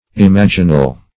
Imaginal \Im*ag"i*nal\, a. [L. imaginalis.]